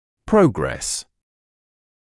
[‘prəugres][‘проугрэс](сущ.) прогресс; ход, течение, развите (о каком-либо процессе); (гл.)